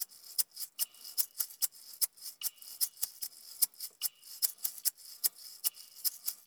SST SHAKER.wav